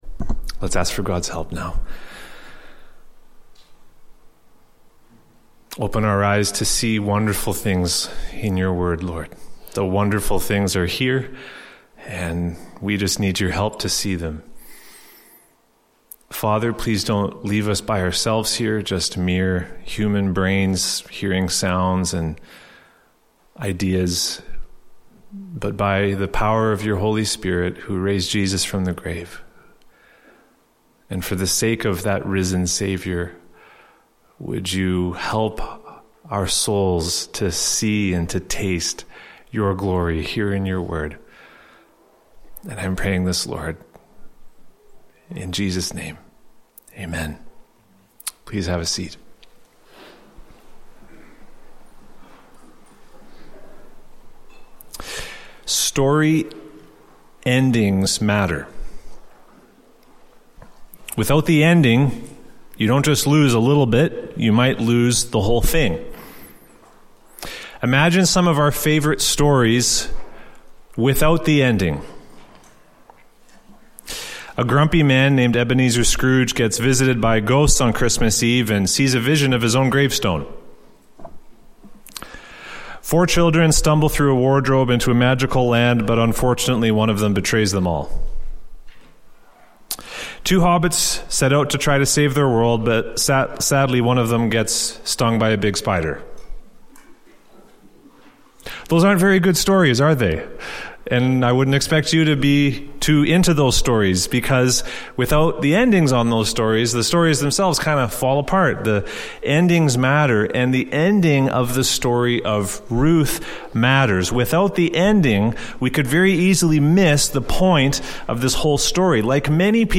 … continue reading 50 episodes # Religion # Sermon Series # Canada # Emmanuel Baptist Church # Christianity